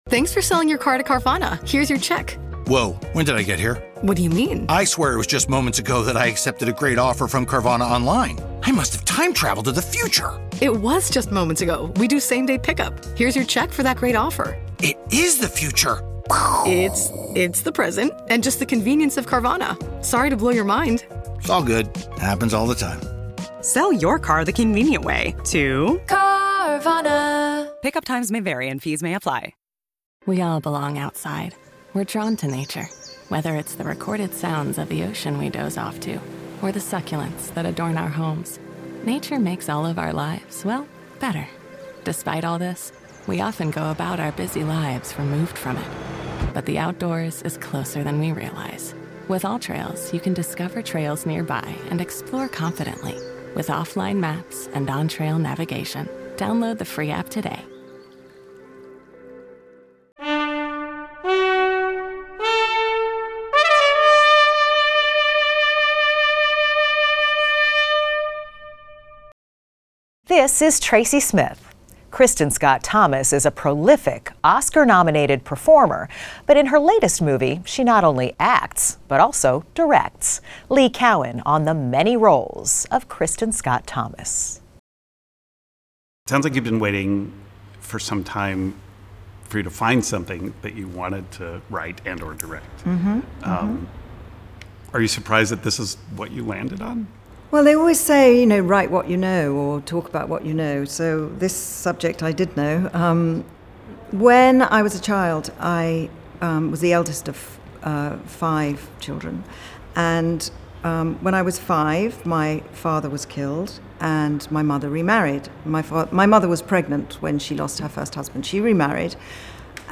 Extended Interview: Kristin Scott Thomas
Kristin Scott Thomas, the Academy Award-nominated actress from "The English Patient," talks with correspondent Lee Cowan about the childhood trauma that inspired her to co-write and direct "My Mother's Wedding." She also talks about her collaboration with actors as a director, and what she learned from other directors; her debut in Prince's "Under the Cherry Moon"; and overcoming her shyness.